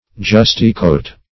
Search Result for " justicoat" : The Collaborative International Dictionary of English v.0.48: Justico \Jus"ti*co\, Justicoat \Jus"ti*coat`\, n. [F. justaucorps, lit., close to the body.] Formerly, a close coat or waistcoat with sleeves.